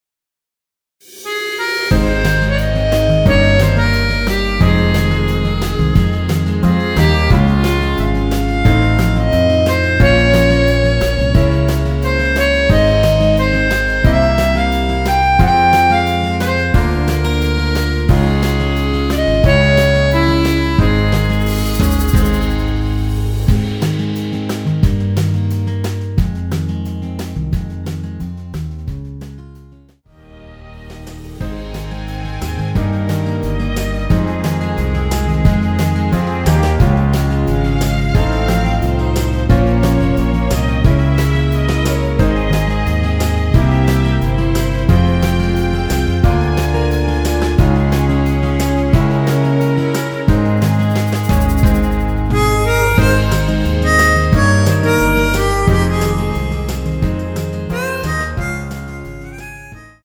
원키에서(-2)내린 MR입니다.
Ab
앞부분30초, 뒷부분30초씩 편집해서 올려 드리고 있습니다.
중간에 음이 끈어지고 다시 나오는 이유는